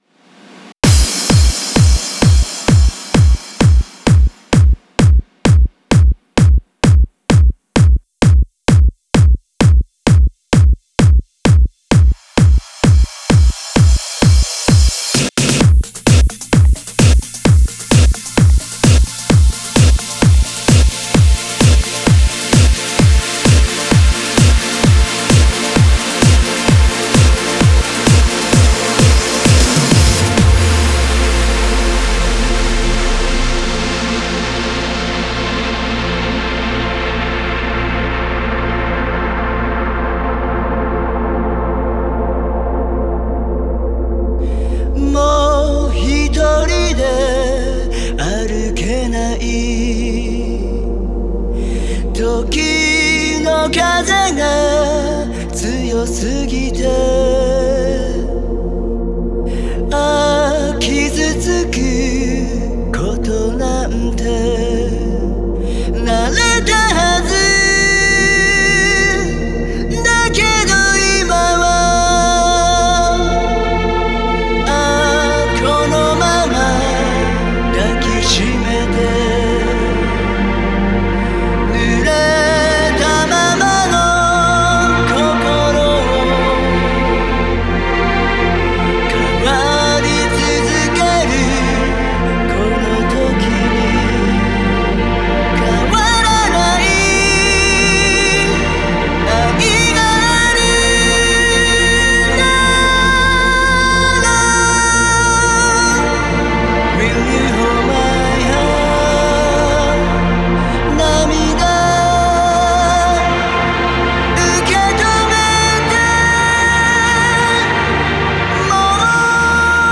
Genre(s): CLUB